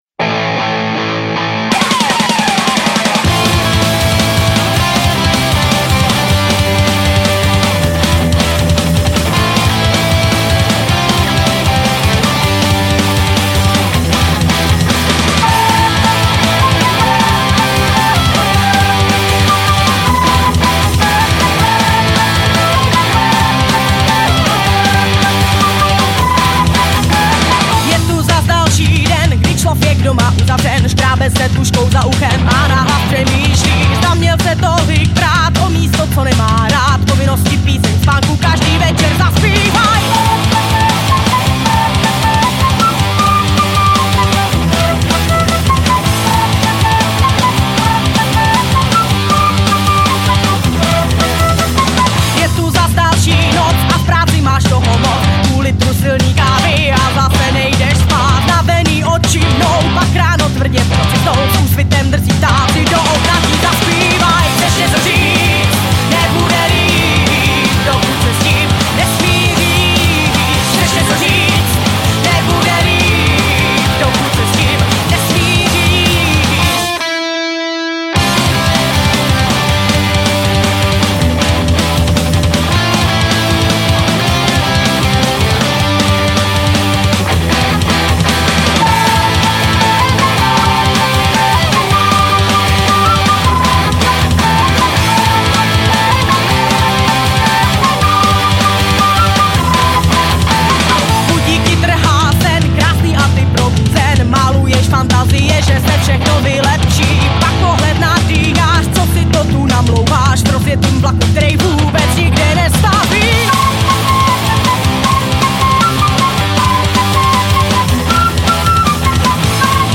Žánr: Punk
punkrockové kapely
se saxofonem a příčnou flétnou